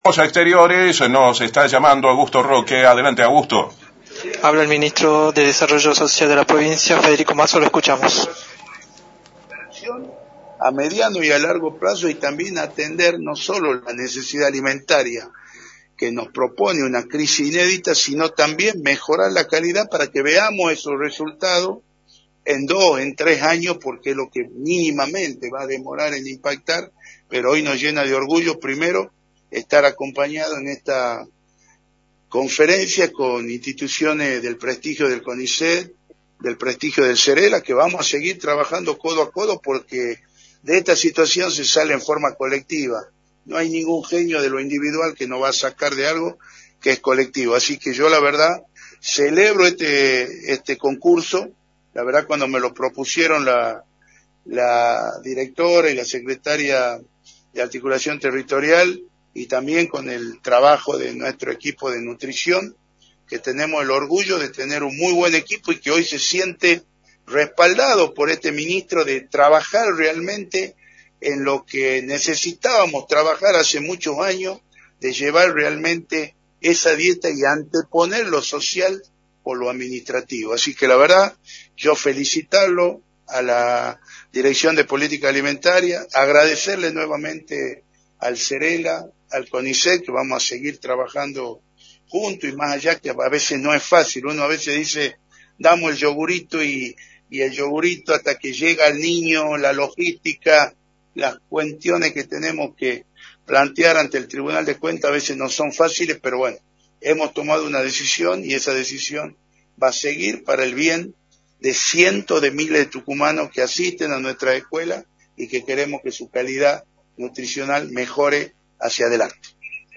“Queremos mejorar la calidad nutricional en los comedores escolares, no solo atacar la necesidad que plantea el hambre, y le agradezco al CONICET y el CERELA por seguir trabajando juntos en este objetivo” señaló Federico Masso en conferencia de prensa.